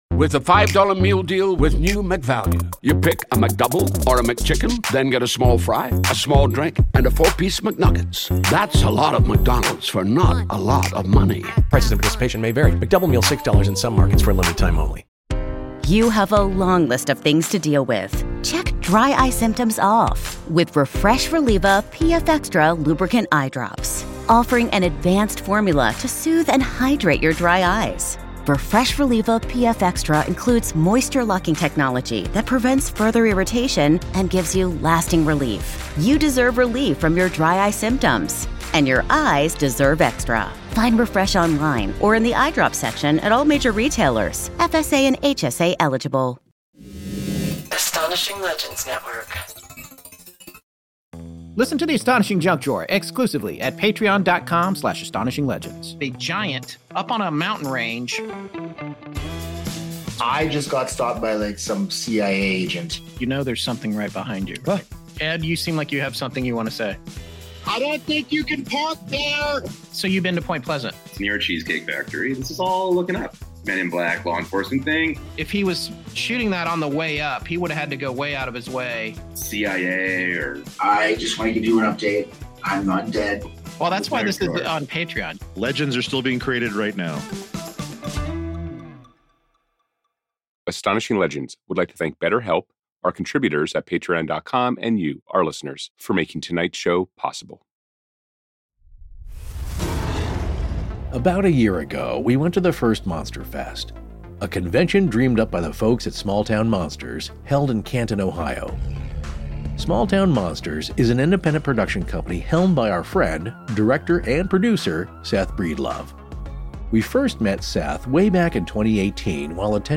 Live from Monster Fest 2 w/ Scared All The Time
Featuring live listener stories and EVP’s collected live on site from the Sallie House DR60!